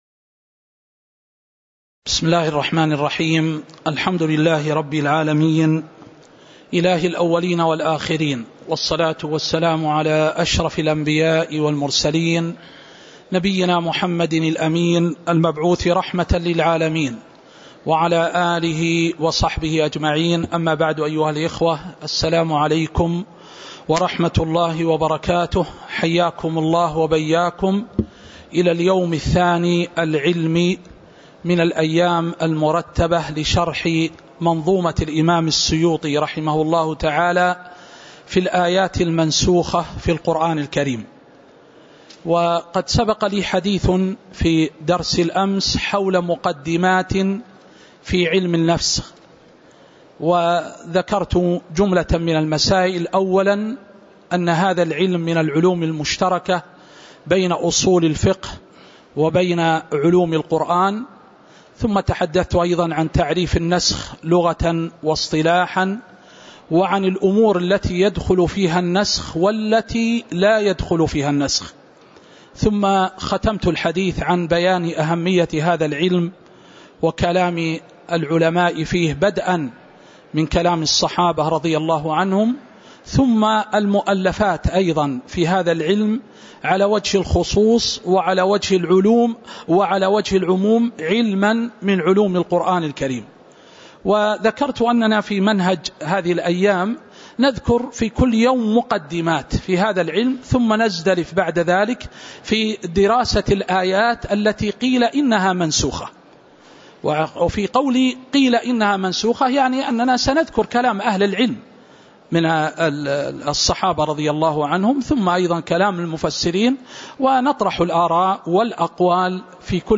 تاريخ النشر ٩ ربيع الثاني ١٤٤٣ هـ المكان: المسجد النبوي الشيخ